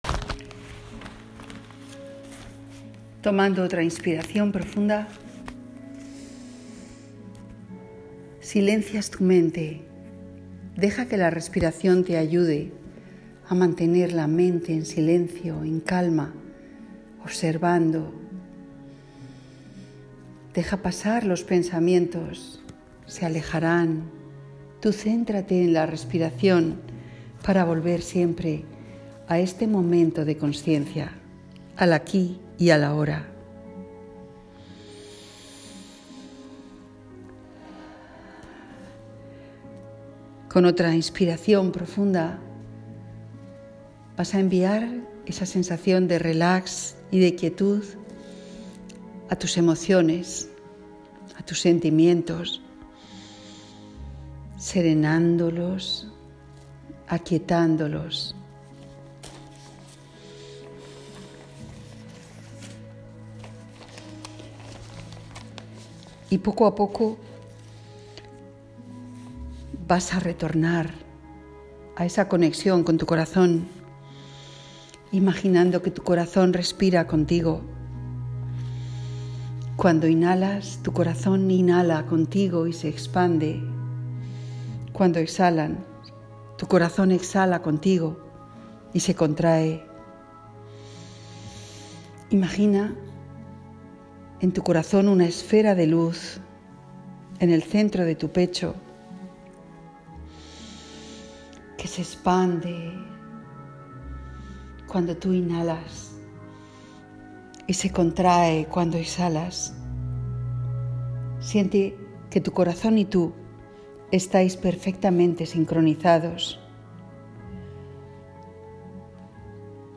Para sintonizar con el momento energético en el que nos encontramos del nacimiento de la primavera, te aconsejo que hagas esta pequeña meditación en la que sembrarás pequeñas semillas de todo lo bueno que quieras atraer a tu vida. Ritual Primavera.m4a (6.94 Mb)